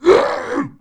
spawners_mobs_uruk_hai_death.1.ogg